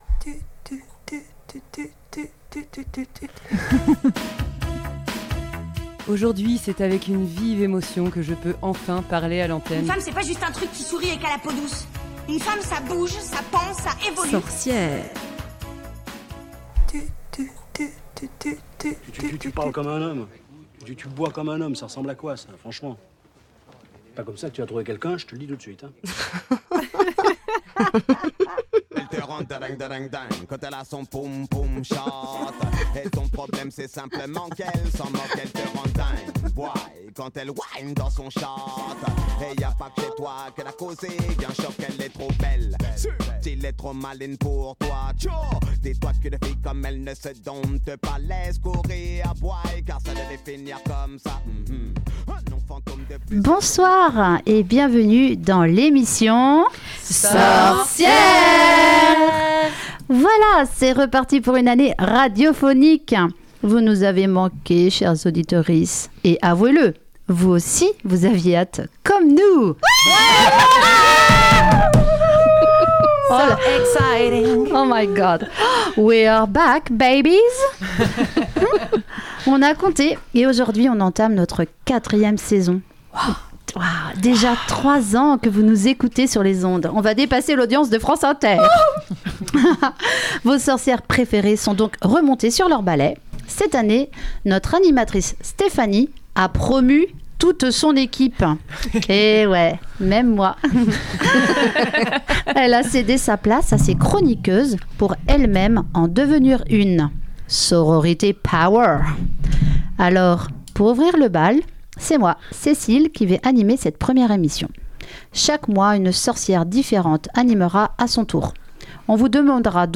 de musique qui font danser!